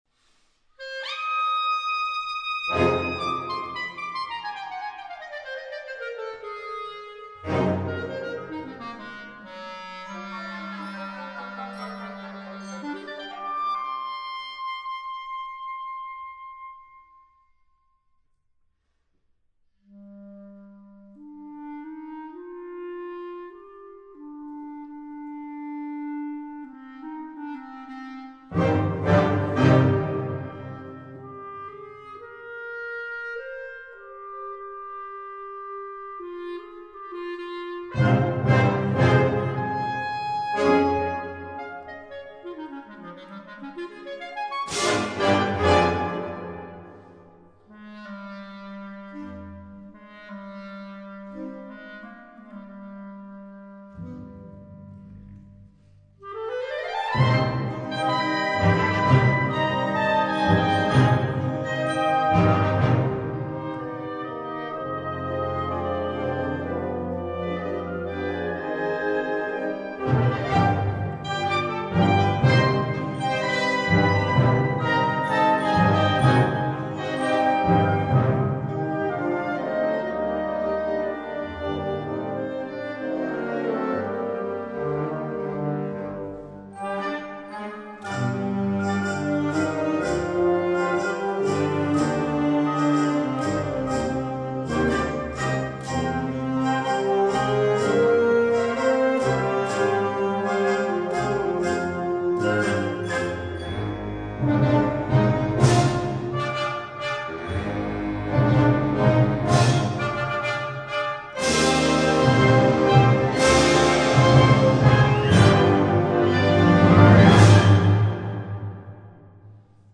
Gattung: Tango
Besetzung: Blasorchester